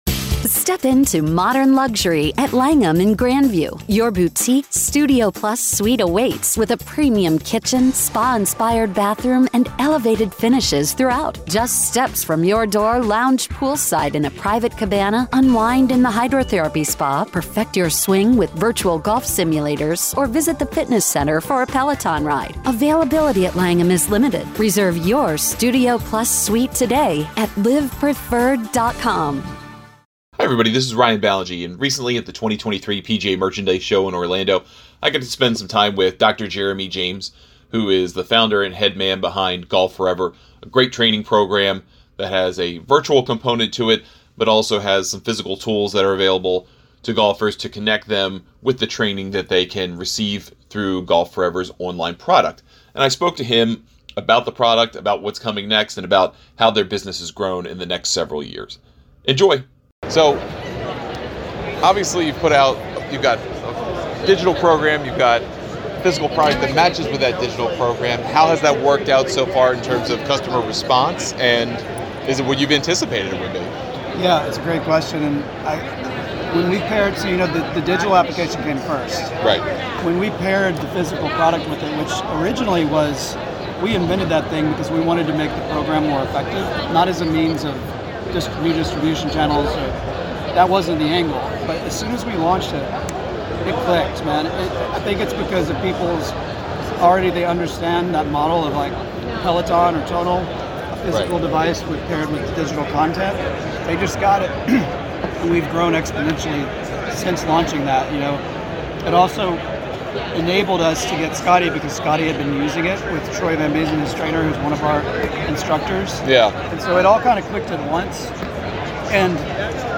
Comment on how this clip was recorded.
In an interview conducted at the 2023 PGA Merchandise Show